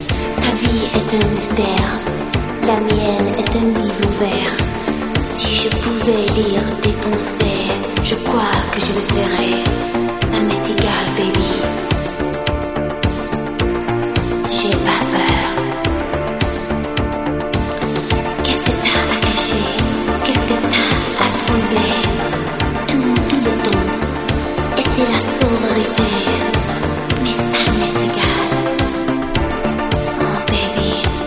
in versione francese